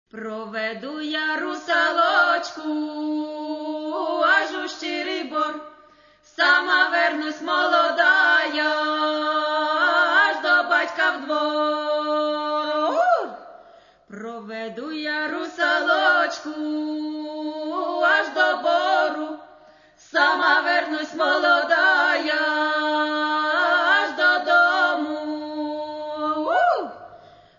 Catalogue -> Folk -> Authentic Performing
Three women – three voices and sometimes three instruments.